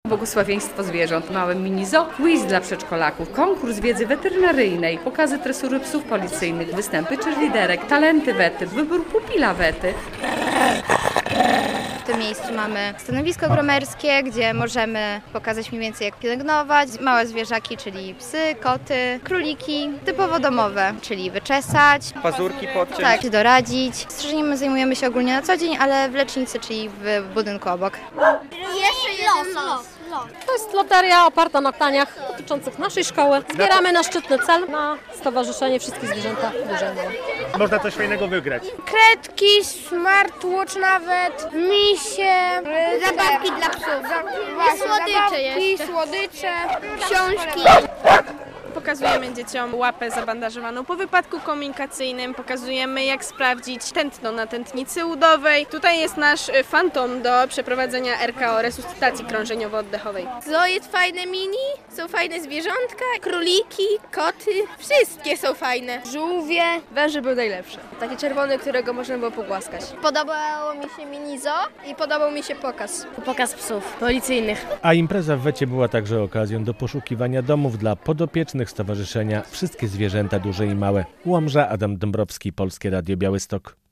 Festyn "Pupile Wety" w Łomży - relacja